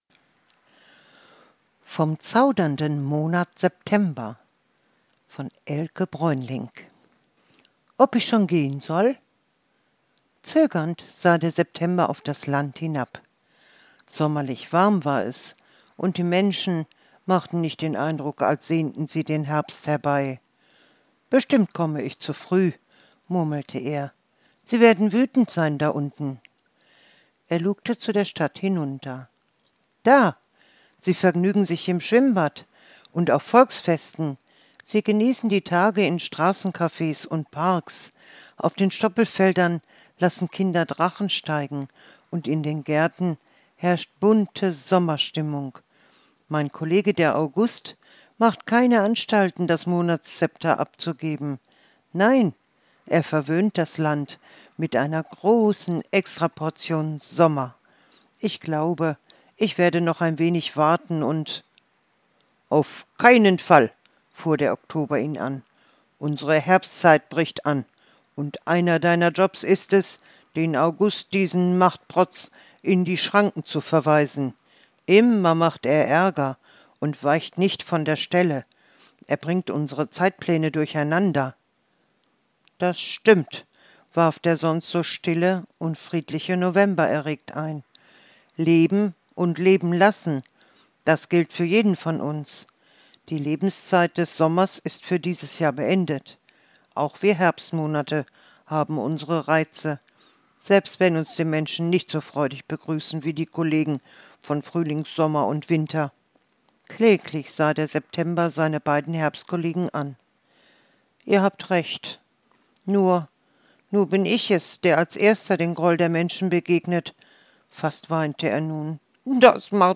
Dieses zauberhafte Monatsmärchen erzählt von Mut, Schönheit und dem leisen Beginn des Herbstes. Mit Ausmalbild und Hörfassung – für kleine und große Träumer.